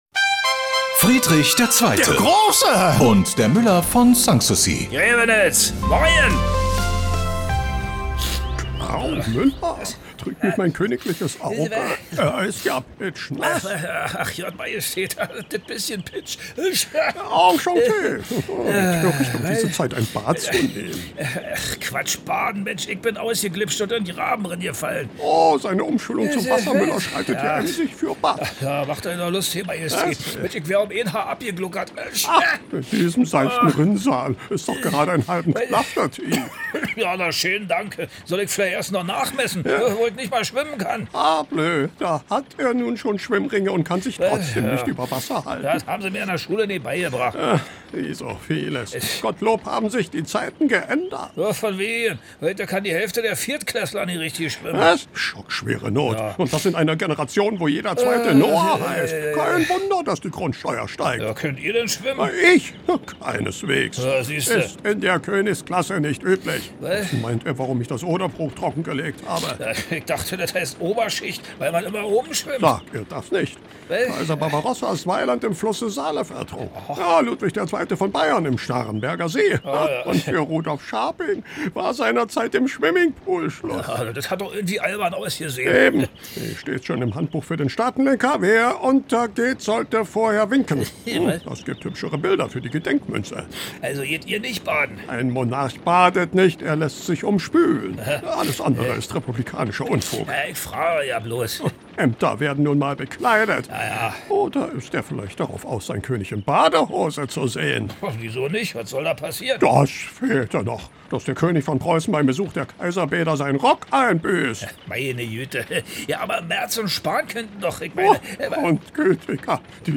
Immer samstags kriegen sich die beiden bei Antenne Brandenburg in die Haare.
Comedy